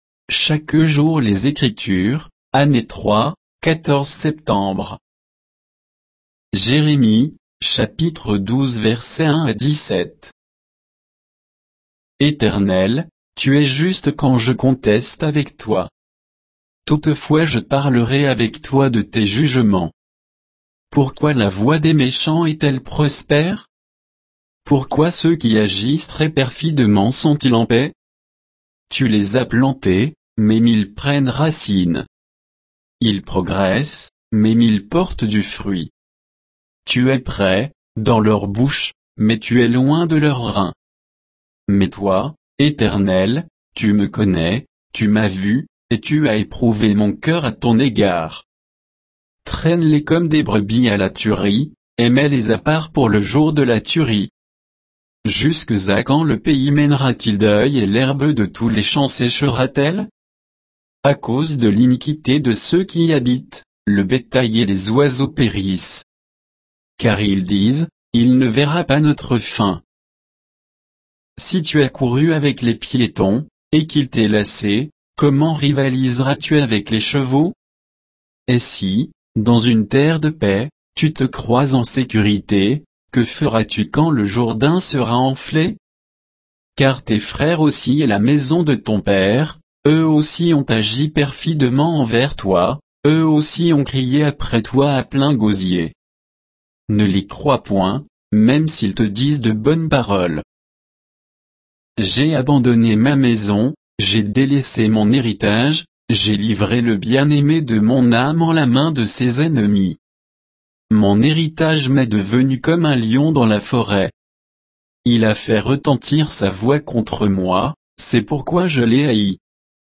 Méditation quoditienne de Chaque jour les Écritures sur Jérémie 12